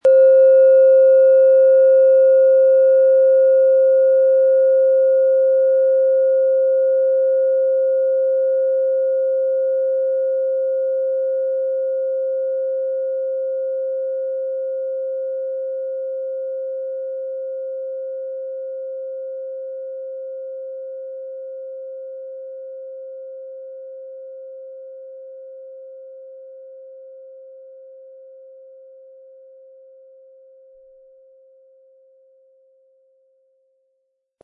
Japanische Klangschale Solfeggio 528 Hz - DNA
Die Klangschale mit 528 Hertz entfaltet einen klaren Ton, der das Herz berührt und innere Balance schenkt.
Sorgfältig gearbeitete Klangschalen mit 528 Hz zeichnen sich durch Reinheit und Klarheit im Ton aus. Der Nachhall wirkt sanft und zugleich tragend, schenkt Geborgenheit und inspiriert zu neuer Kraft.
MaterialBronze